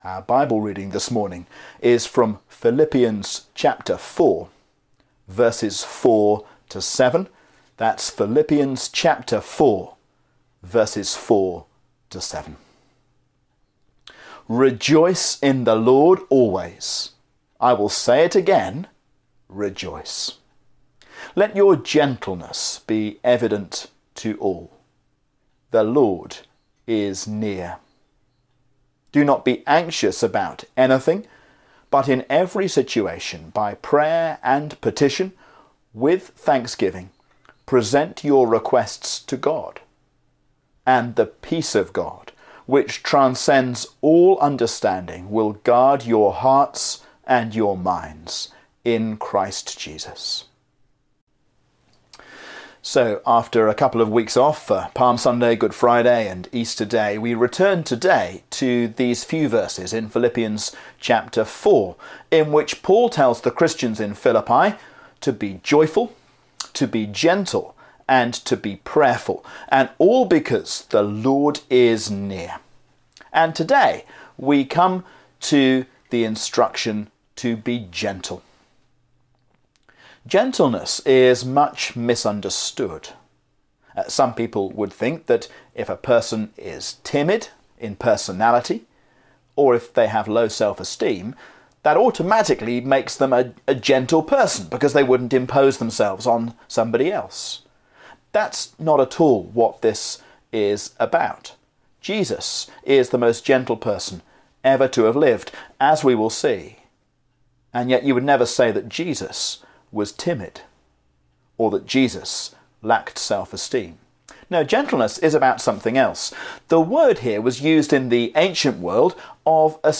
A sermon on Philippians 4:4-7